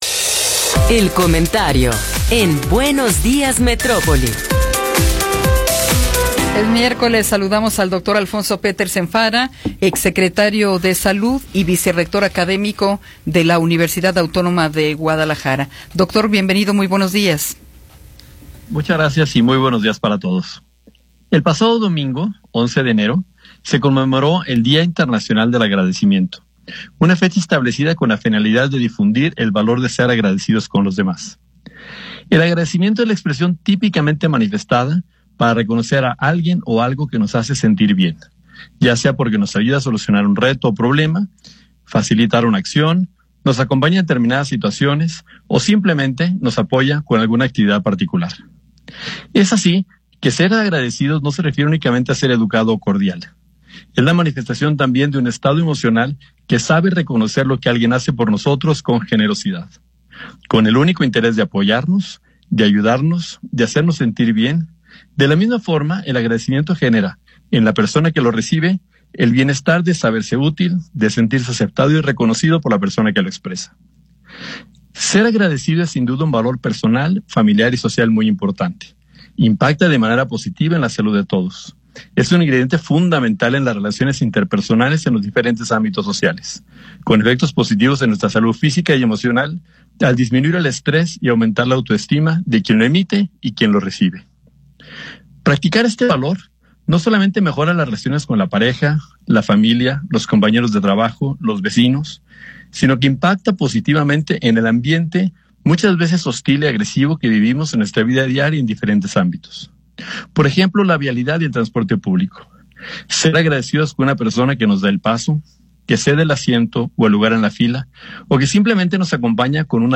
El Dr. Alfonso Petersen Farah, vicerrector de ciencias de la salud de la UAG y exsecretario de salud del estado de Jalisco, nos habla sobre la conmemoración del Día Internacional del Agradecimiento.